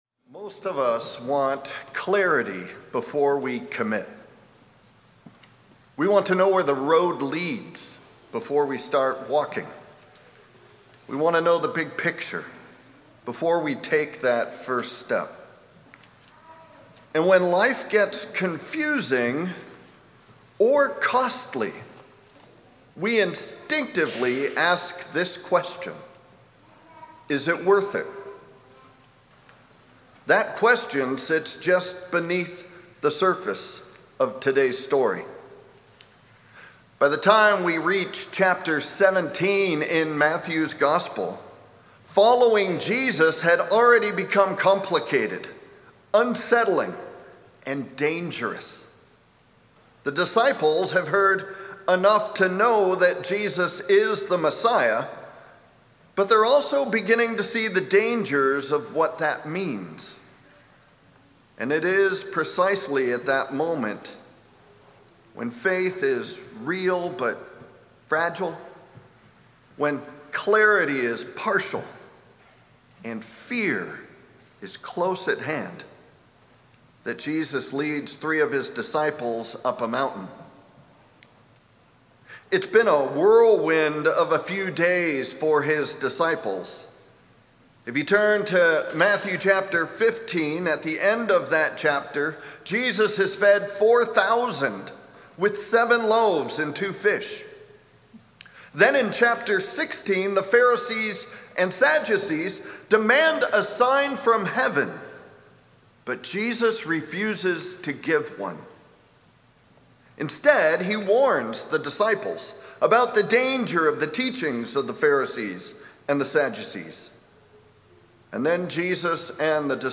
Sermon+2-15-26.mp3